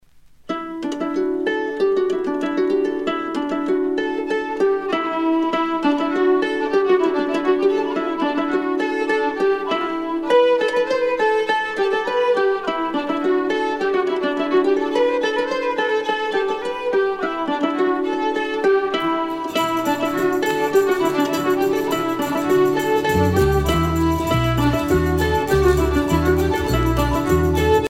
danse : an dro
Pièce musicale éditée